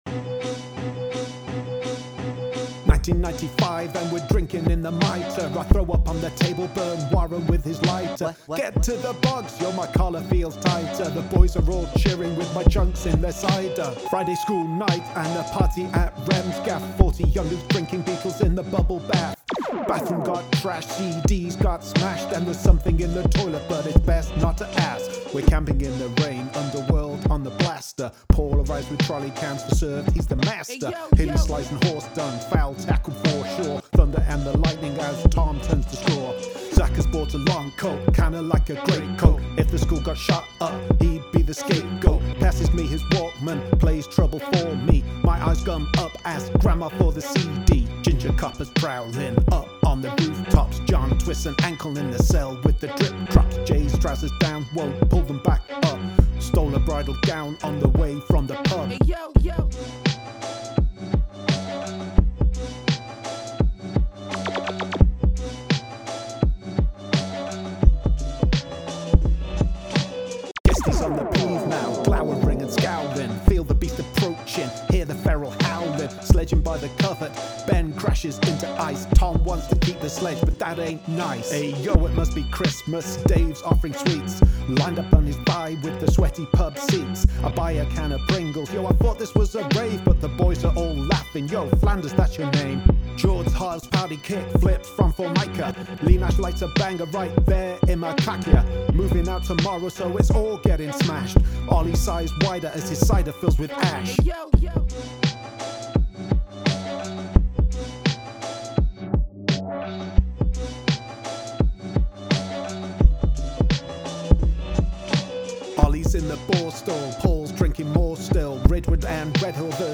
beat
so I rapped about our adolescence on it.